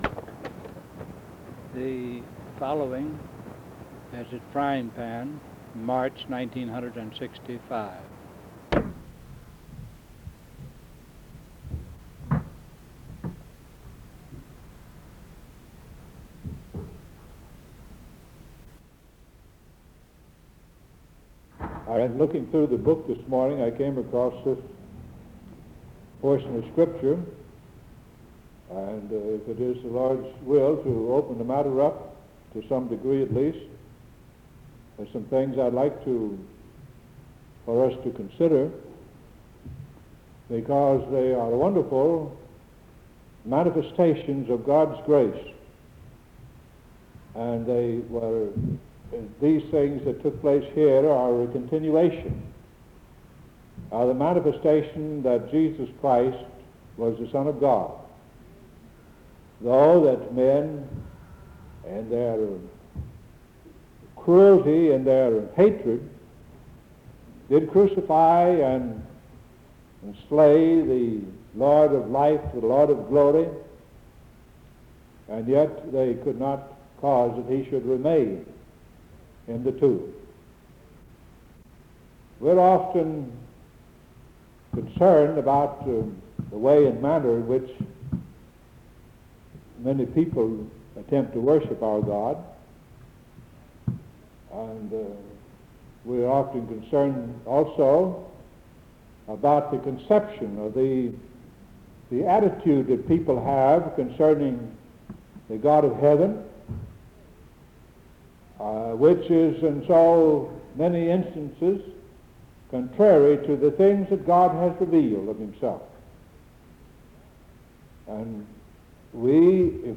Sermon on the fatherhood of God being exclusive to the elect, who believe in correct doctrine